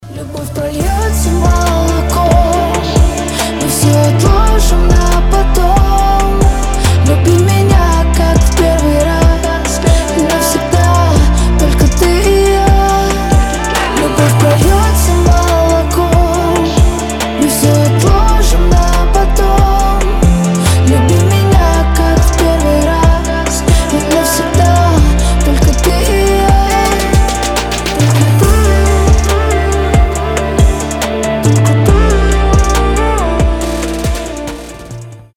• Качество: 320, Stereo
красивые
чувственные
медленные